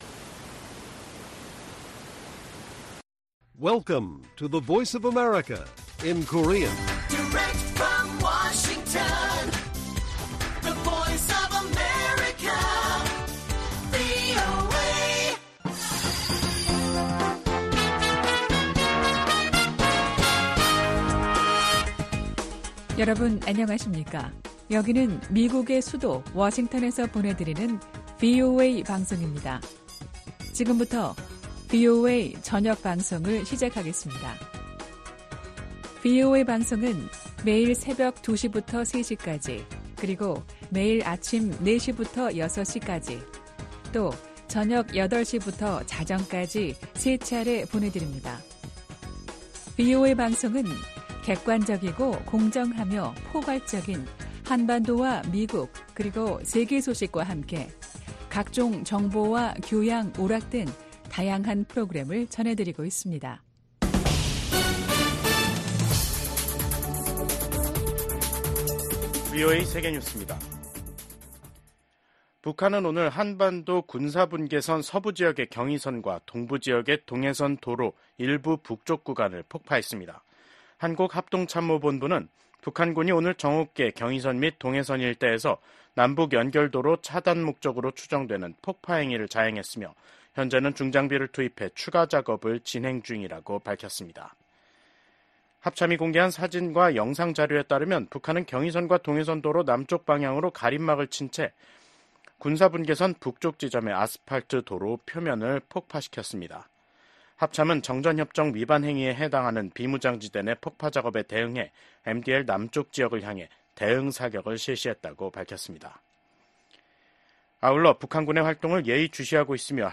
VOA 한국어 간판 뉴스 프로그램 '뉴스 투데이', 2024년 10월 15일 1부 방송입니다. 북한의 ‘한국 무인기 평양 침투’ 주장으로 한반도 긴장이 고조되고 있는 가운데 북한군이 오늘(15일) 남북을 잇는 도로들을 폭파했습니다. 16일 서울에서 미한일 외교차관협의회가 열립니다.